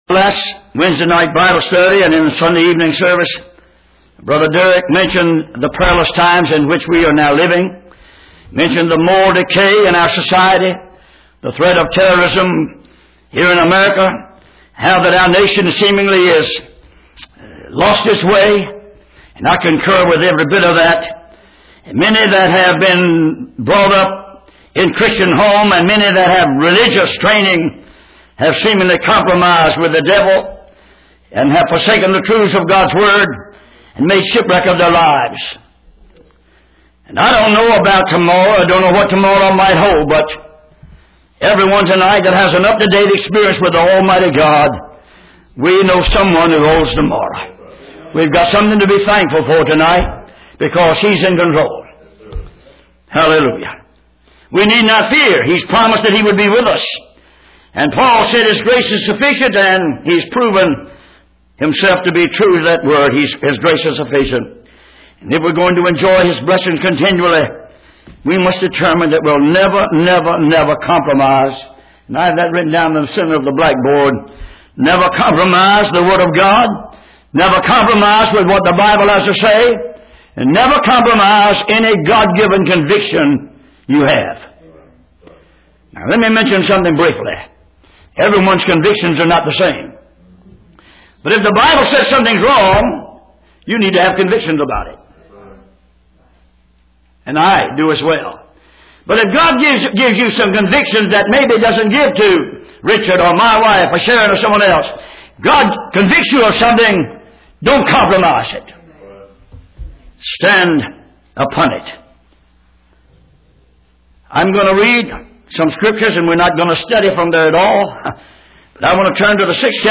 Passage: Exodus 10:1-10 Service Type: Sunday Evening